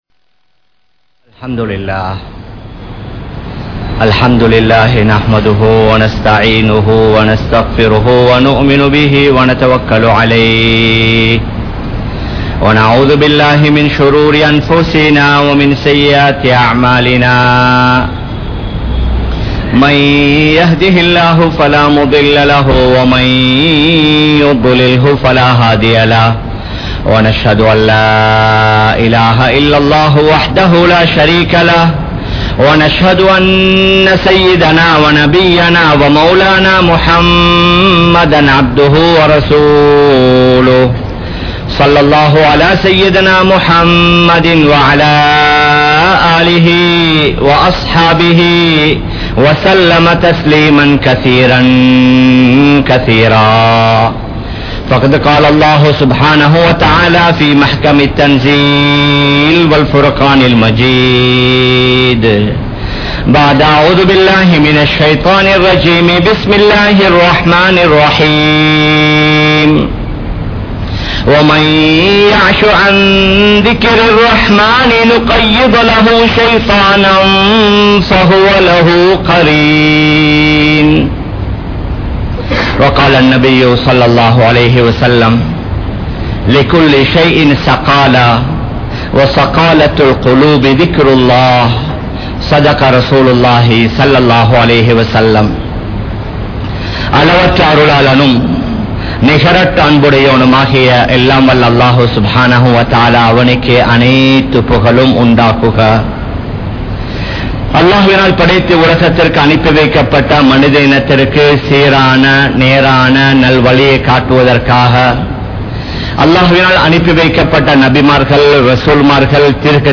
Alukkadaintha Ullangal (அழுக்கடைந்த உள்ளங்கள்) | Audio Bayans | All Ceylon Muslim Youth Community | Addalaichenai